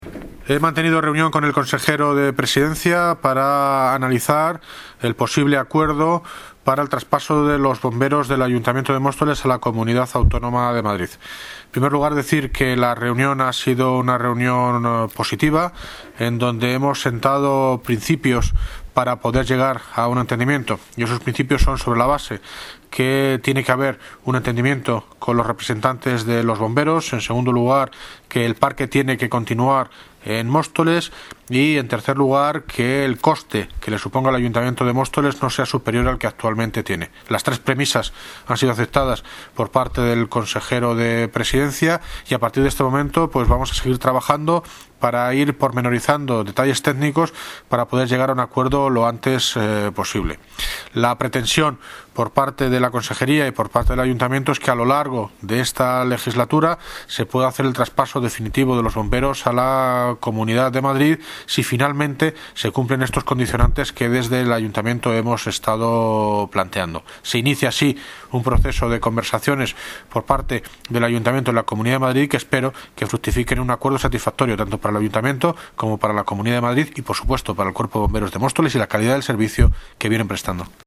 Audio - David Lucas (Alcalde de Móstoles) Sobre Reunión Consejero de Presidencia